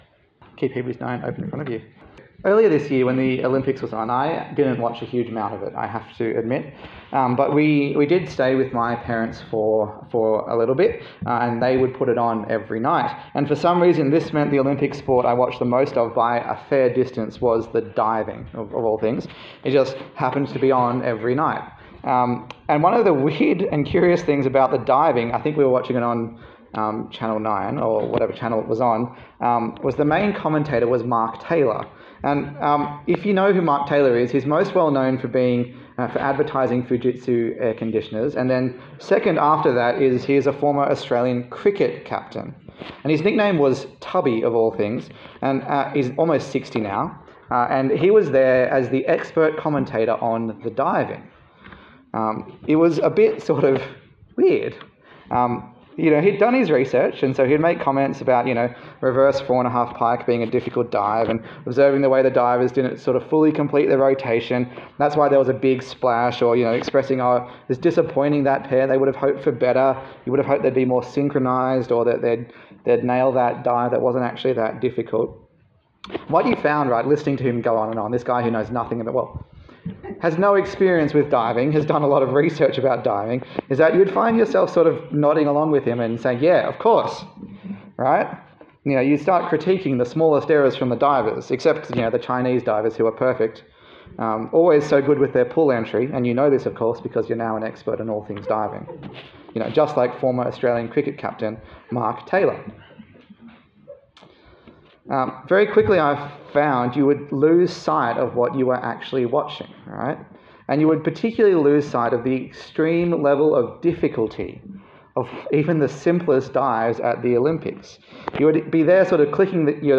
A sermon in the series on the letter to the Hebrews
Service Type: Sunday Service